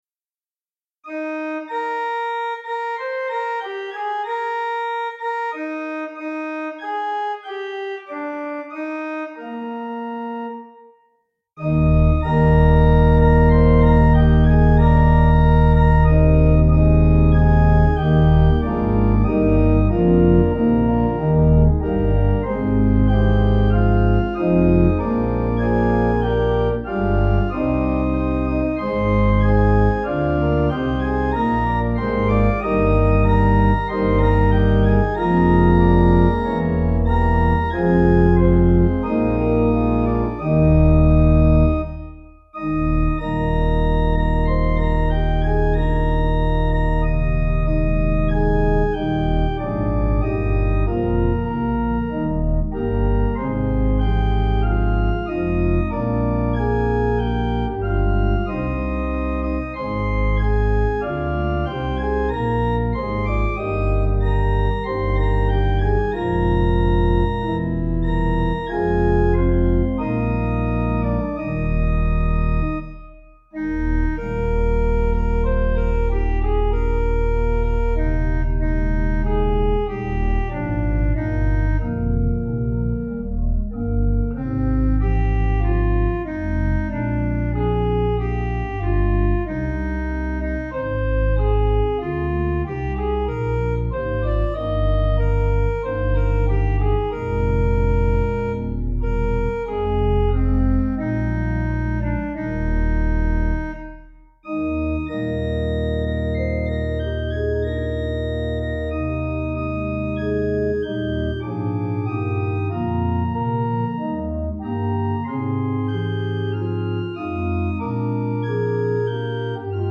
Dear Lord and Father of mankind – Organist on demand
Organ: Little Waldingfield